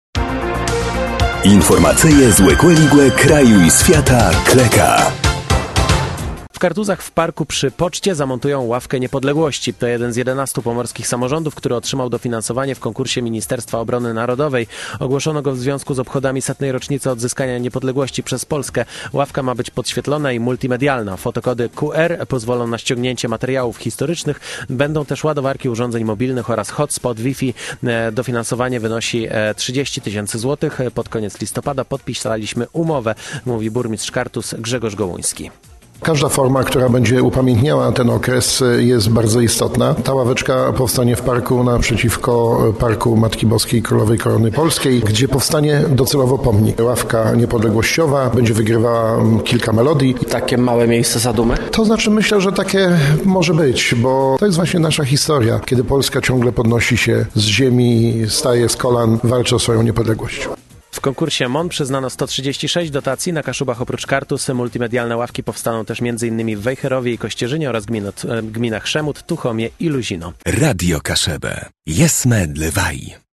– Dofinansowanie wynosi 30 tys zł, pod koniec listopada podpisaliśmy umowę – mówi burmistrz Kartuz Grzegorz Gołuński.